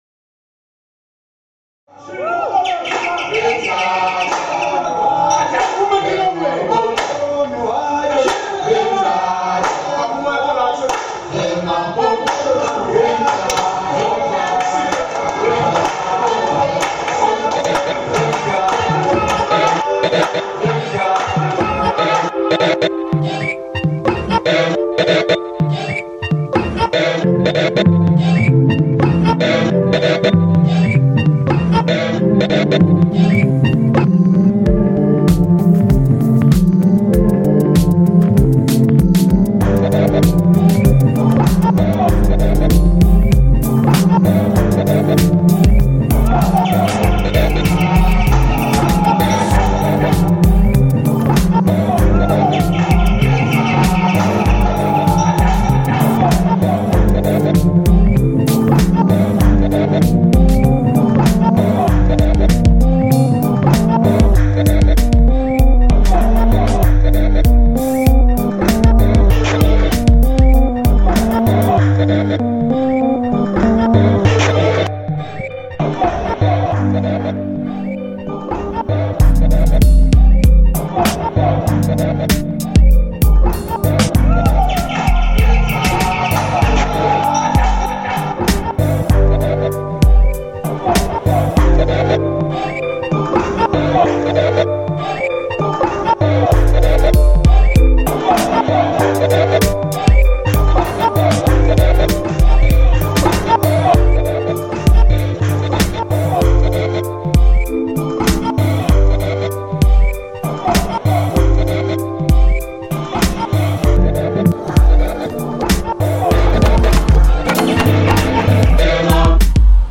Imfolozi Park, South Africa